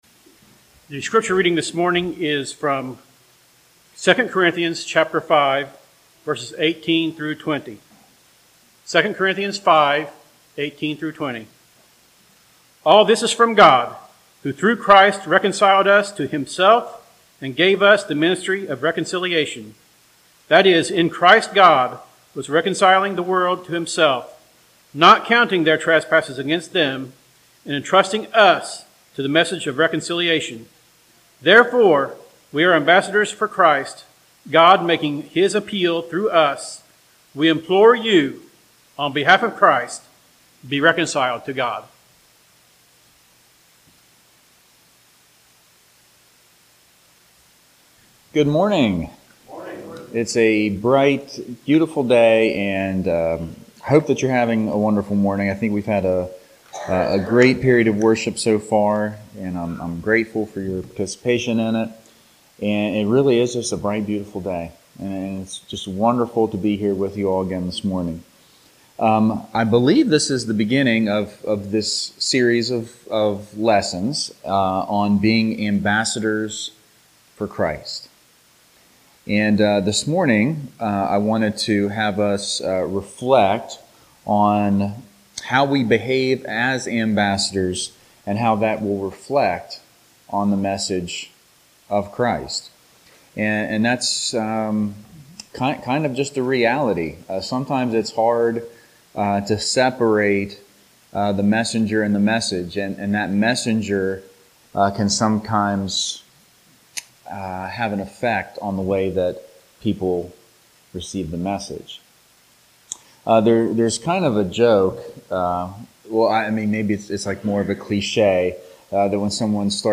Ambassadors for Christ Passage: 2 Corinthians 5:15-20 Service: Sunday Morning